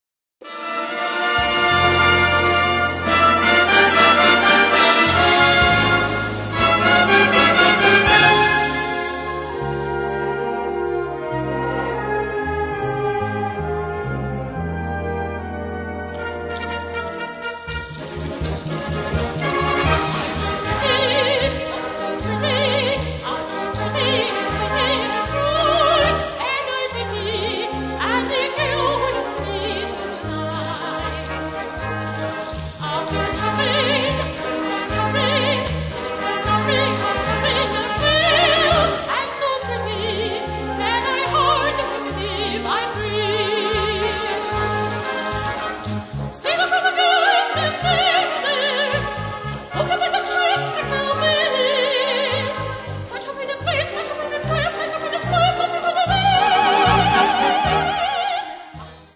Gattung: Selection
Besetzung: Blasorchester